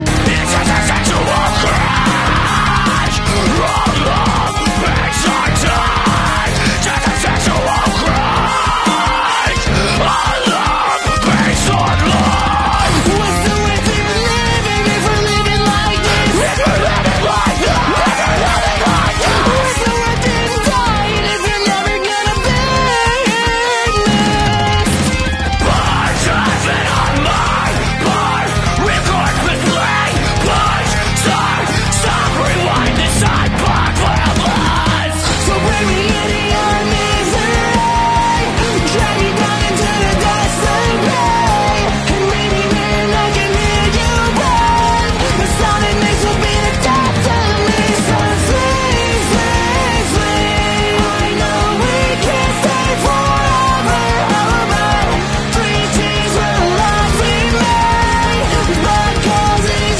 Post-Hardcore / Metalcore